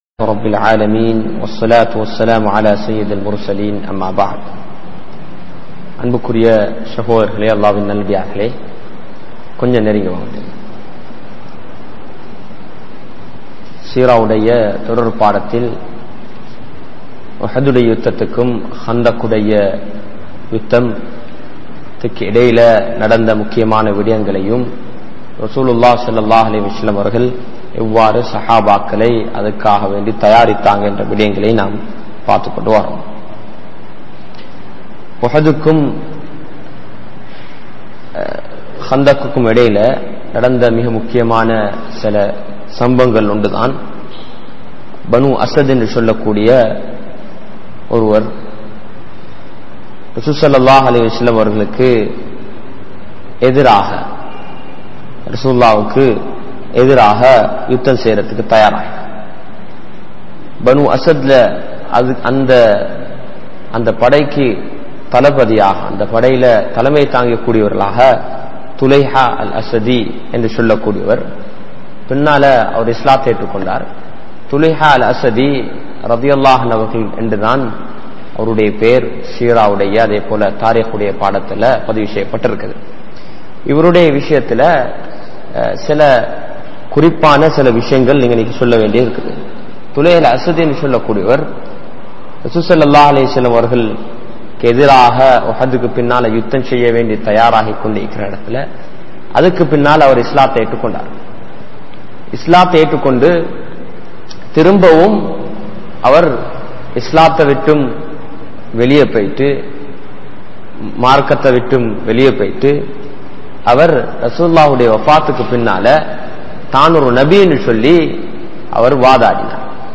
Seerah Of Prophet Muhammed(SAW) | Audio Bayans | All Ceylon Muslim Youth Community | Addalaichenai
Muhiyadeen Jumua Masjith